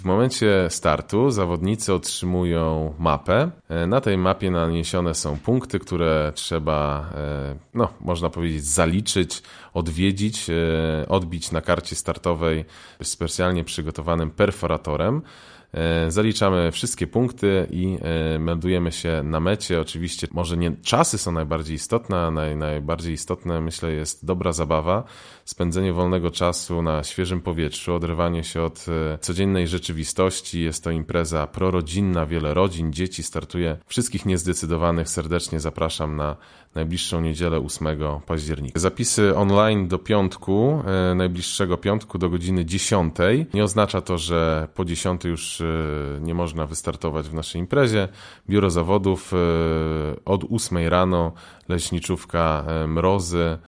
mówił na antenie Radia 5